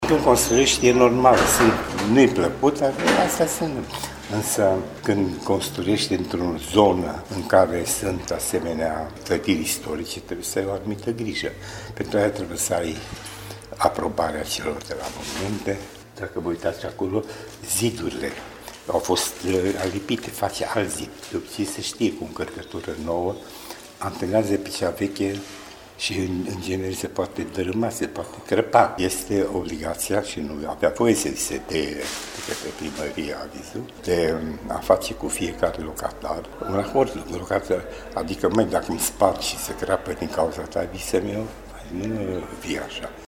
locatar-asociatie-centru.mp3